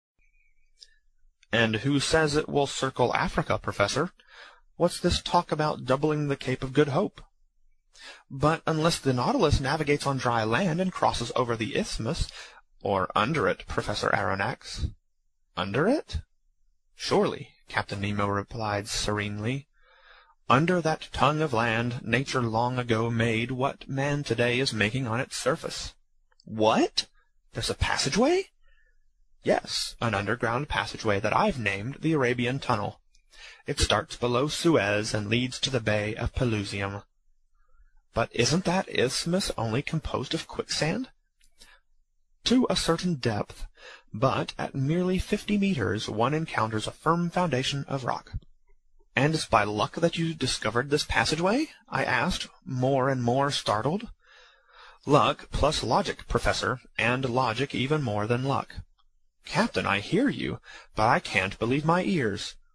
英语听书《海底两万里》第354期 第23章 珊瑚王国(51) 听力文件下载—在线英语听力室
在线英语听力室英语听书《海底两万里》第354期 第23章 珊瑚王国(51)的听力文件下载,《海底两万里》中英双语有声读物附MP3下载